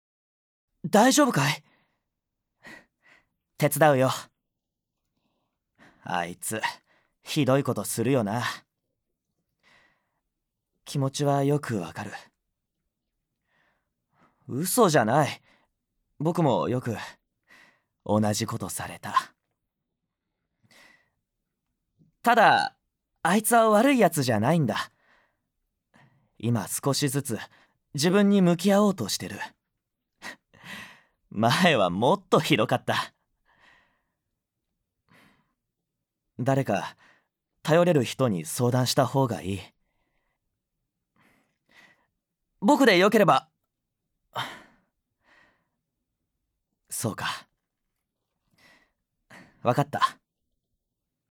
ボイスサンプル
●セリフ①20歳前後のさわやかな青年